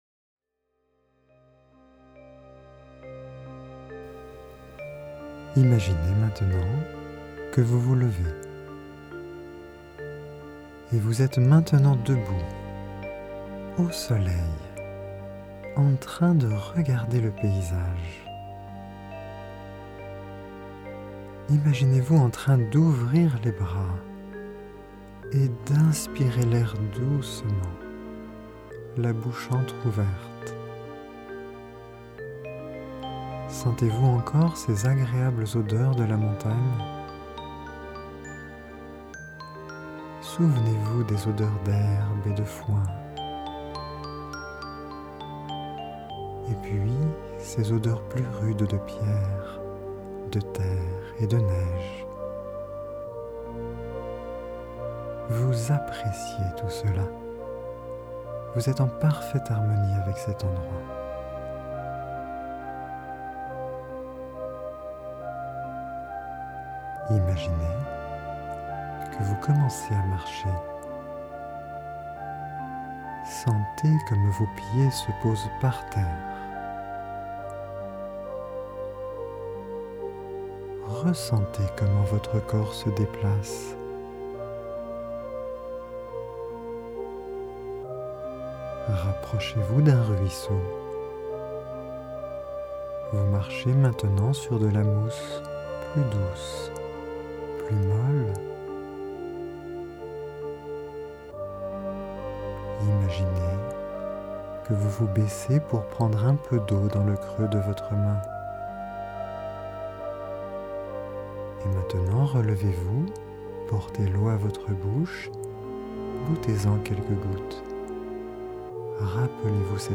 Genre : Meditative.